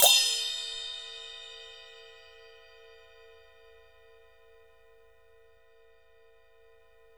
2 CYMBALS -R.wav